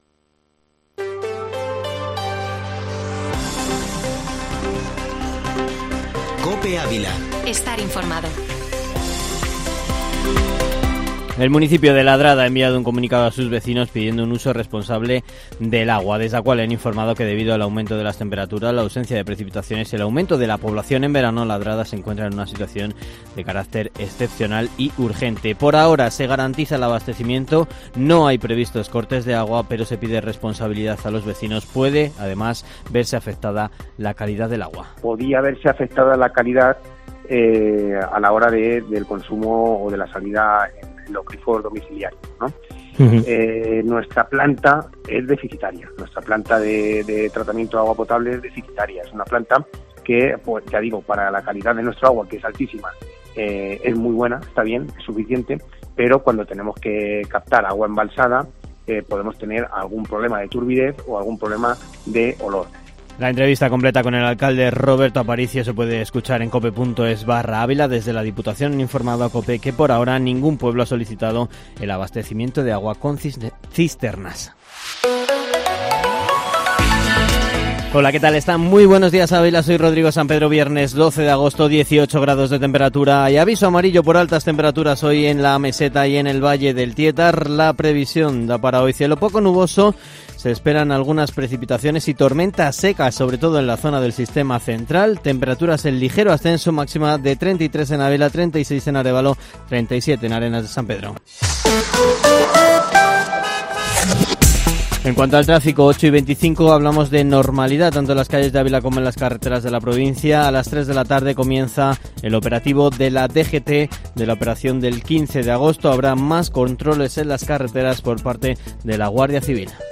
Informativo Matinal Herrera en COPE Ávila -12-agosto